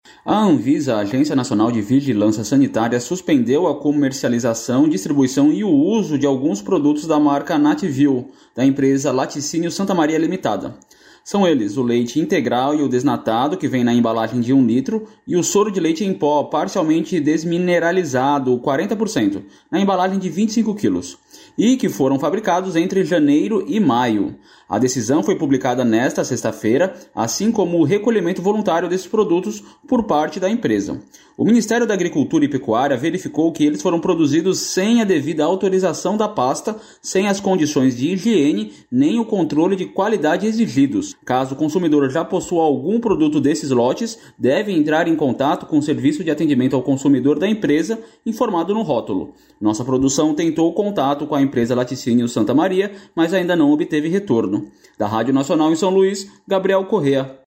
repórter da Rádio Nacional
De São Luís